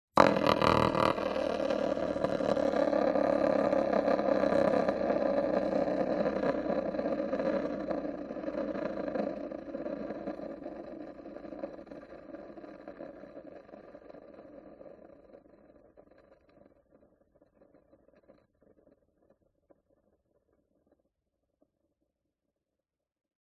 Волчок - Альтернативная версия